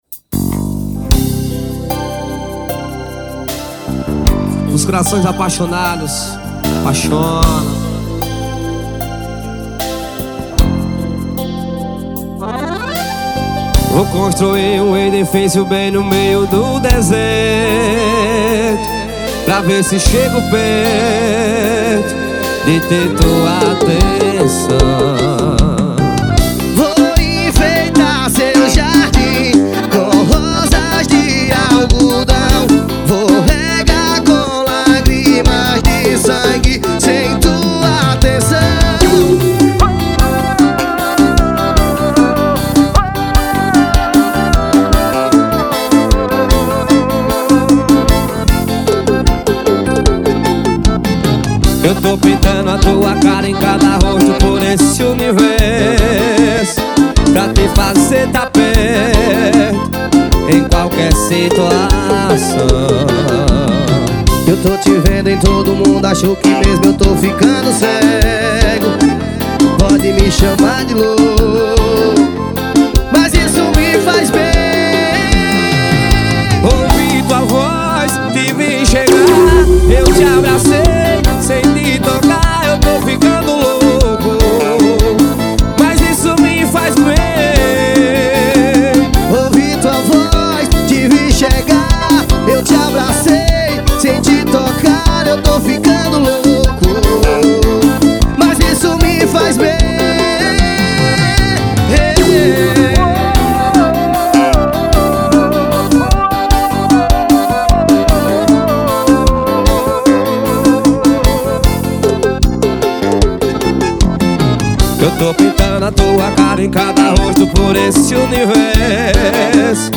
2024-02-14 18:17:42 Gênero: Forró Views